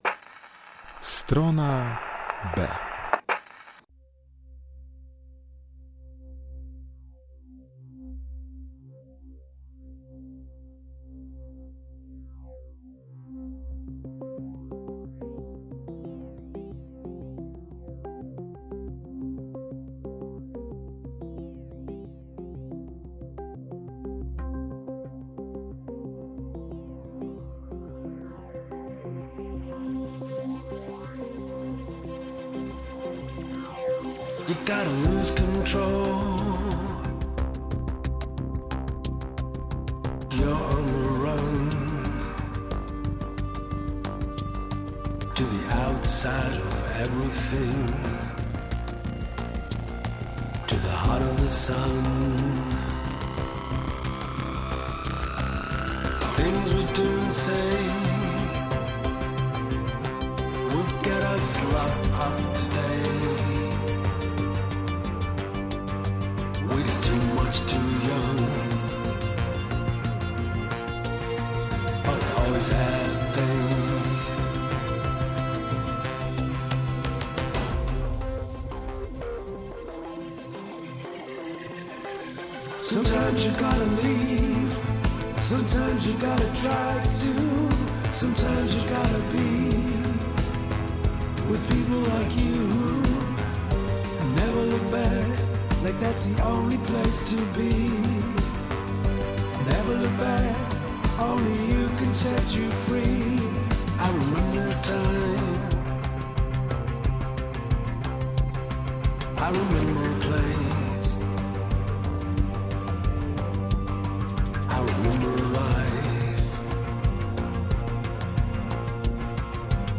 STRONA B - godzina z elektroniką, ambient, post punkiem i shoegaze.